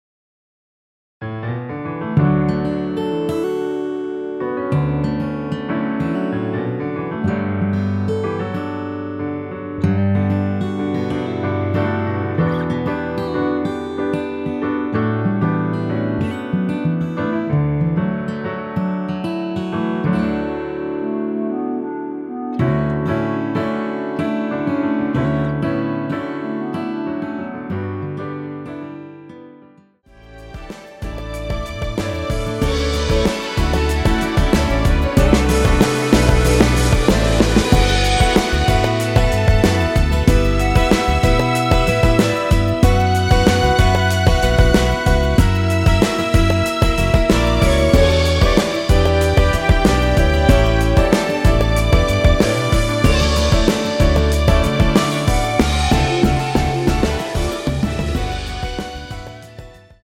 원키에서(+1)올린 멜로디 포함된 MR입니다.
D
◈ 곡명 옆 (-1)은 반음 내림, (+1)은 반음 올림 입니다.
앞부분30초, 뒷부분30초씩 편집해서 올려 드리고 있습니다.
중간에 음이 끈어지고 다시 나오는 이유는